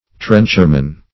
Trencher-man \Trench"er-man\, n.; pl. Trencher-men.